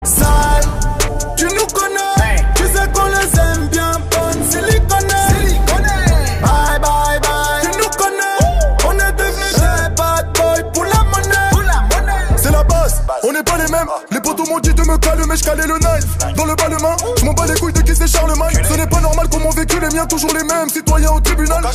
Rap - Hip Hop